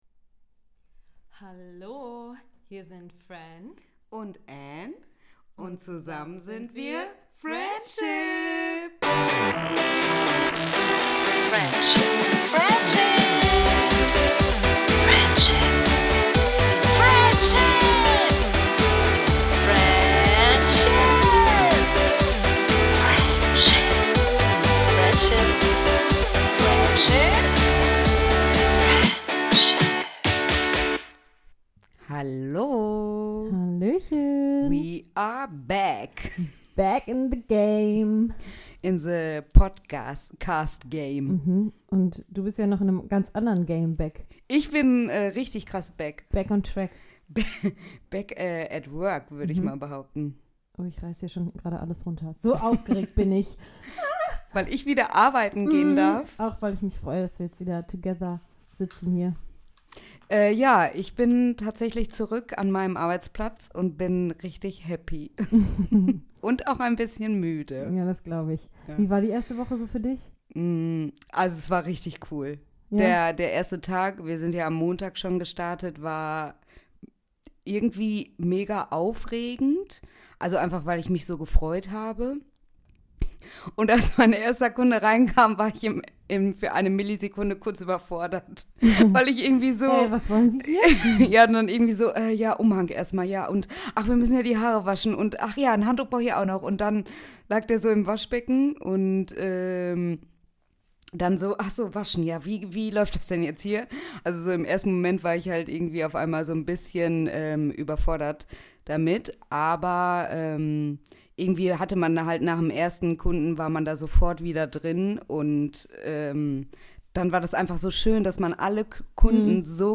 Kurzer Girlstalk und absolut wissenswertes Halbwissen.